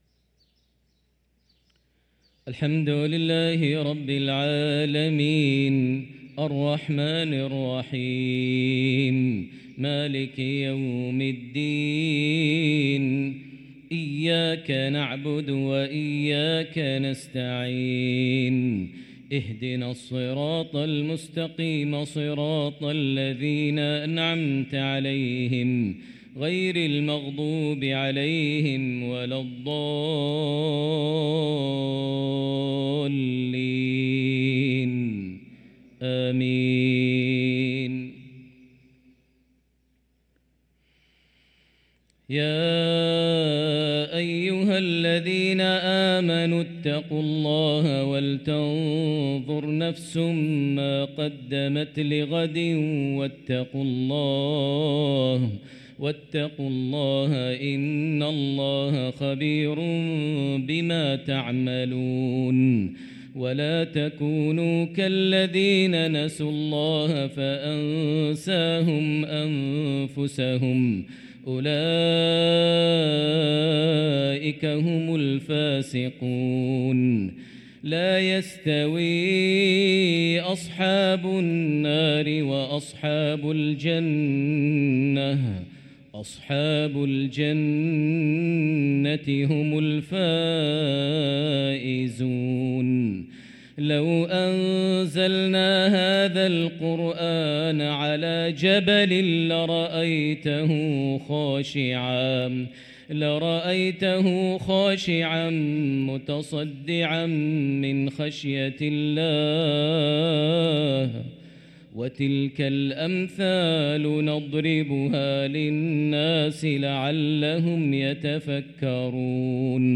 صلاة العشاء للقارئ ماهر المعيقلي 18 جمادي الأول 1445 هـ
تِلَاوَات الْحَرَمَيْن .